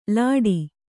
♪ lāḍi